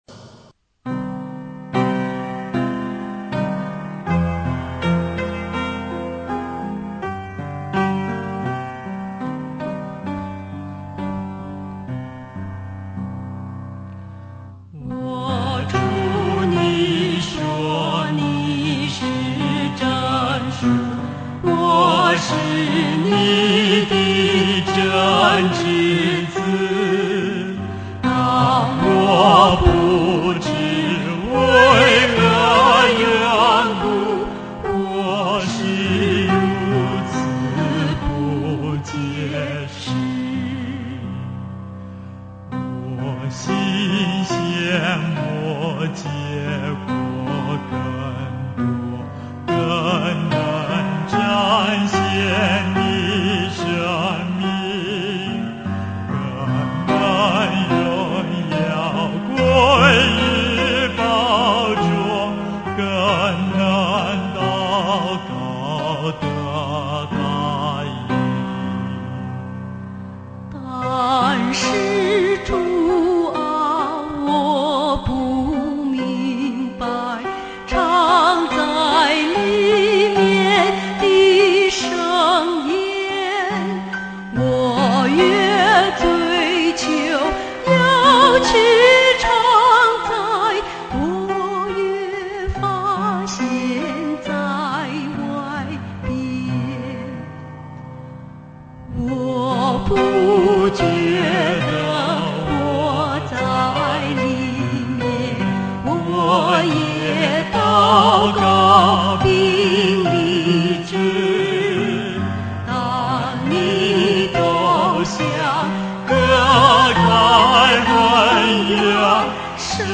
收聽 純音樂: (僅供參考。